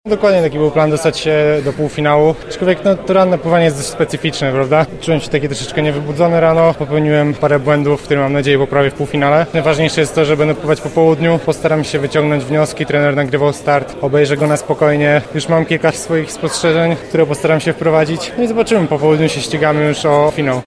Sam Tomasz Polewka potwierdza, że wykonał założony plan: